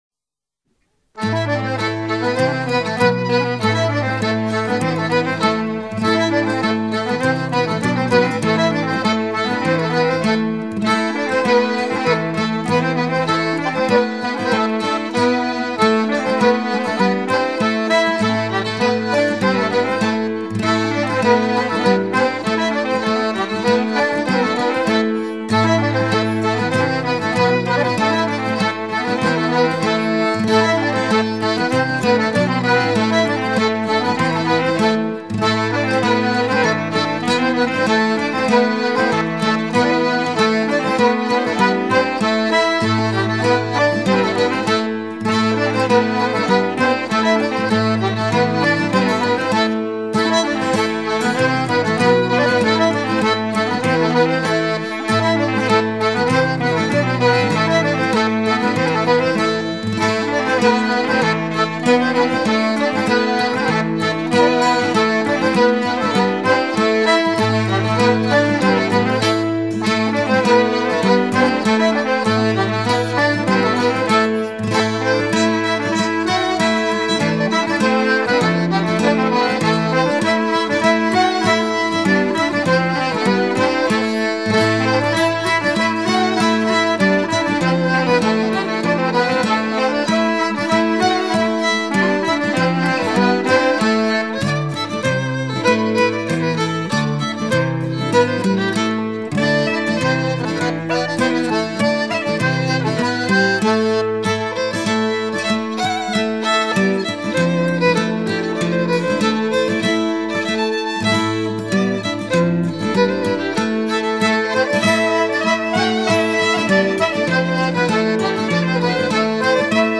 Deux airs vannetais
danse : kas-a-barh